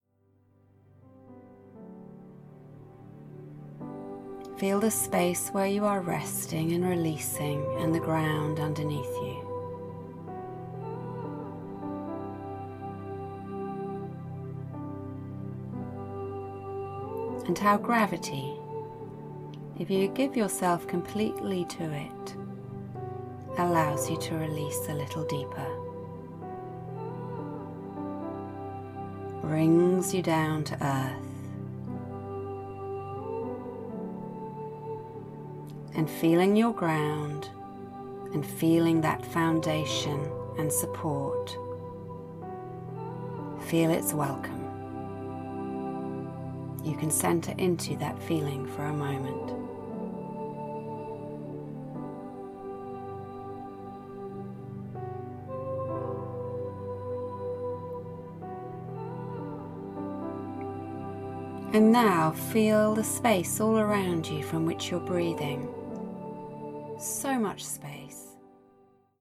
The album contains 19 tracks and 4 hours of recordings including guided meditations, instructional material and “re-sets”.